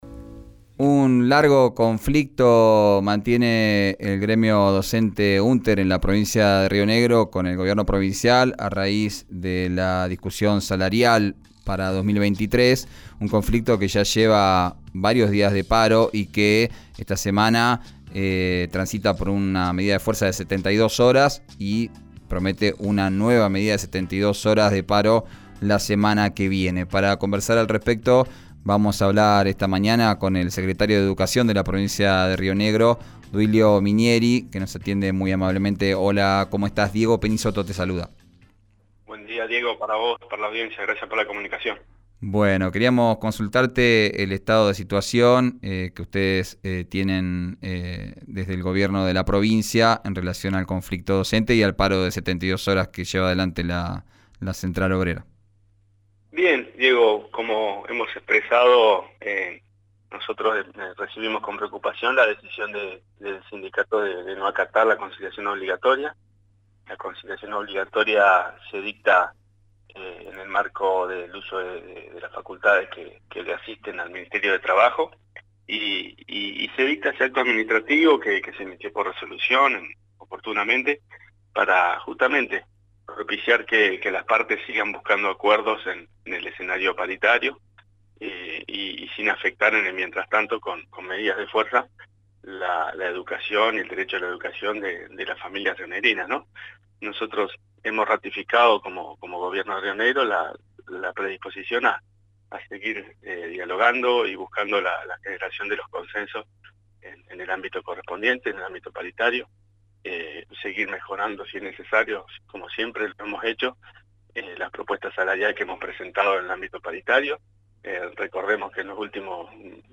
Minieri explicó la situación actual en «Arranquemos» por RÍO NEGRO RADIO.